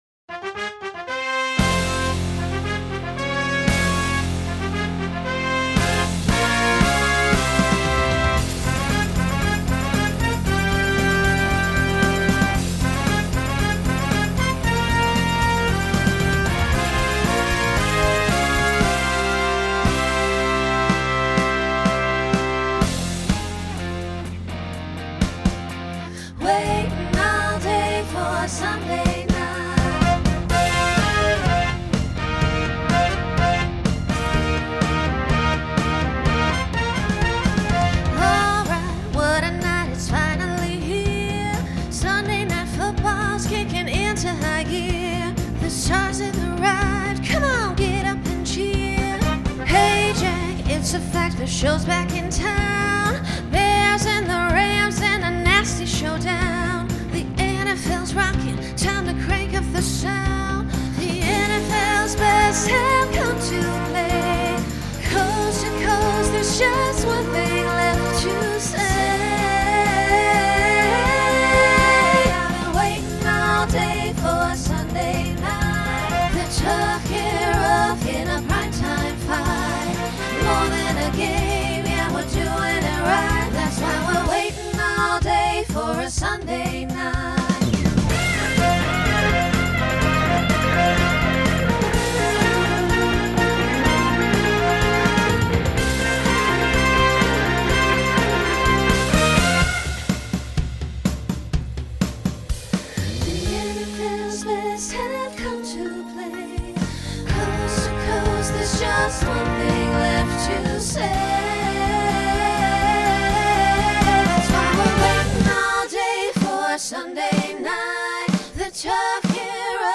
New SATB voicing for 2025.
Genre Rock Instrumental combo
Voicing SATB , SSA